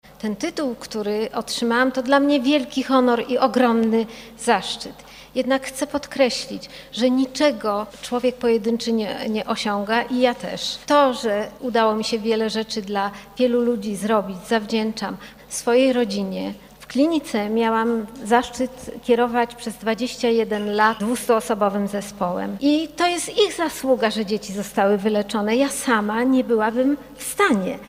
-Niczego nie udałoby się osiągnąć w pojedynkę. Ta nagroda to dla mnie wielki honor i zaszczyt – mówiła podczas uroczystości w Ratuszu prof. Alicja Chybicka.